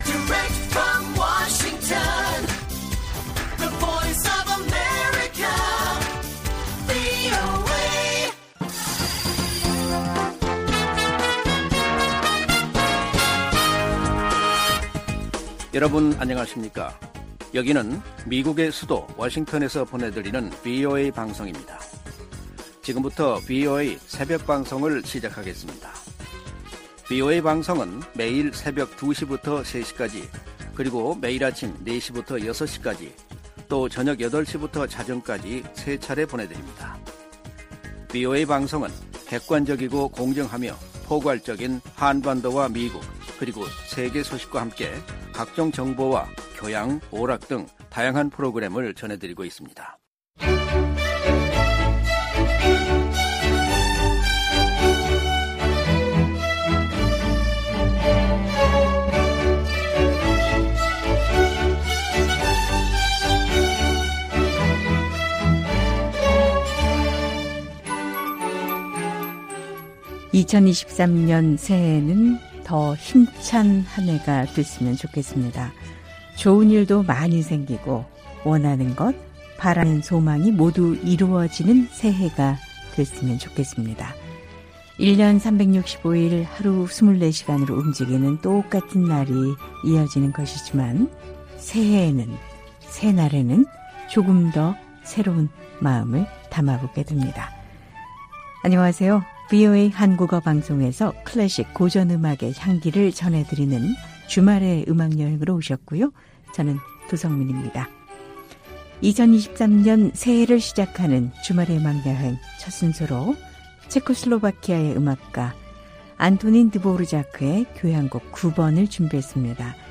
VOA 한국어 방송의 일요일 새벽 방송입니다. 한반도 시간 오전 2:00 부터 3:00 까지 방송됩니다.